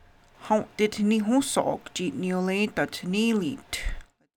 Click below each toggle to hear the Onyotaʼa:ká: and English pronunciations.